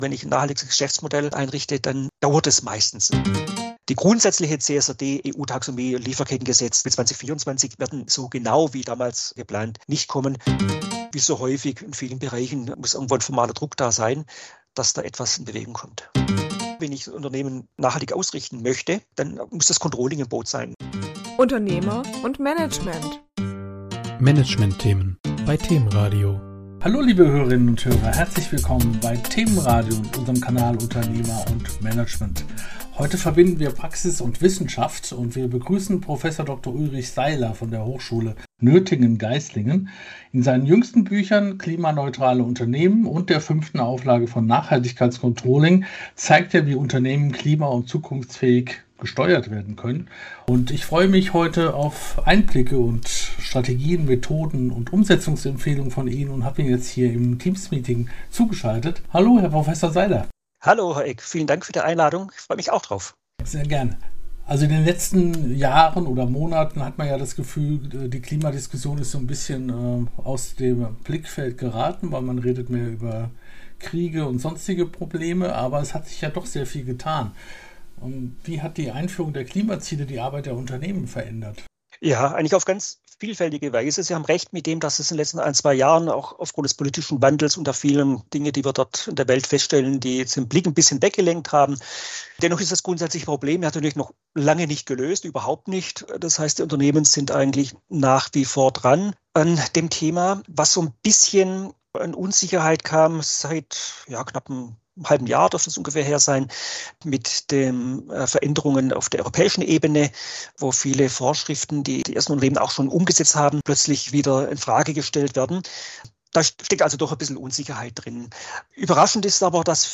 Im Interview: